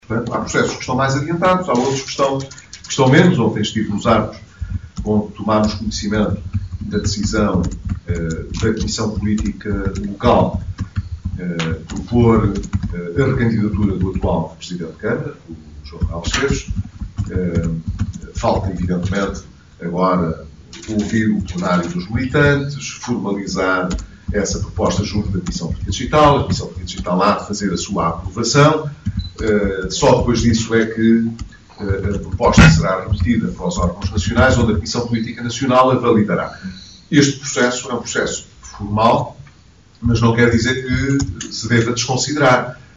Declarações de Pedro Passos Coelho em Viana do Castelo